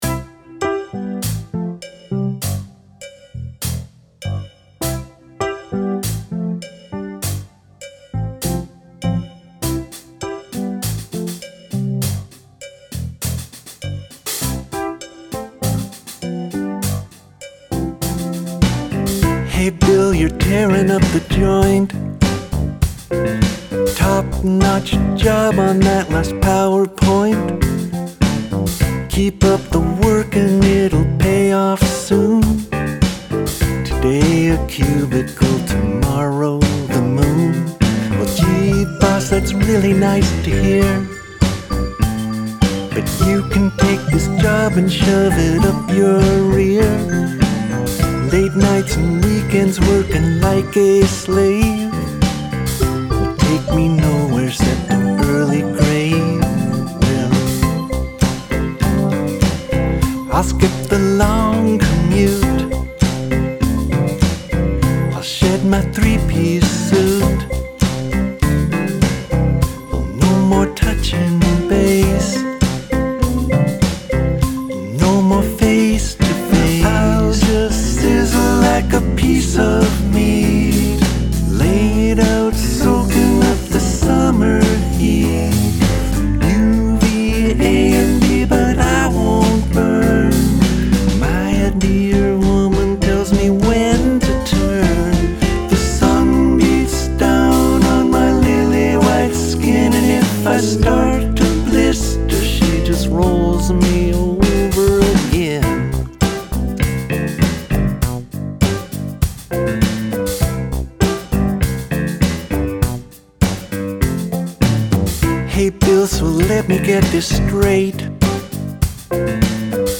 ultra-high harmonies